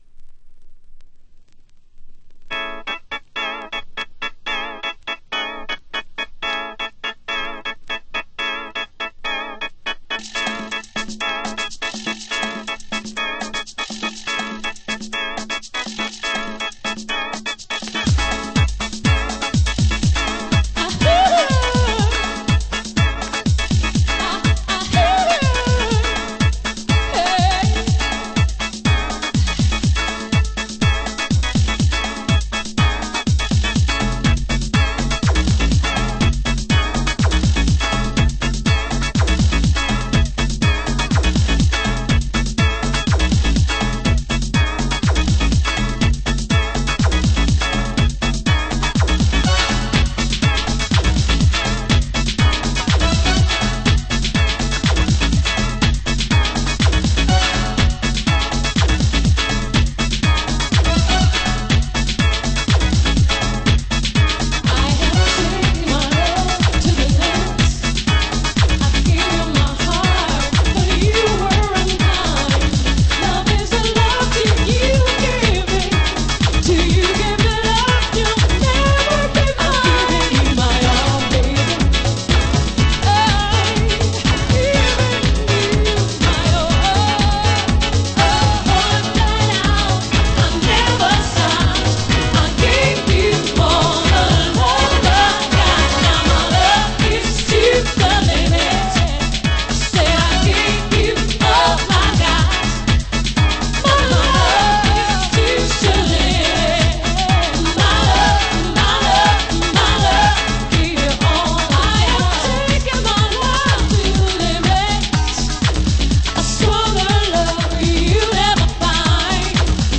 盤質：A1/B1のイントロに小傷によるプチノイズ有（どちらも試聴箇所になっています）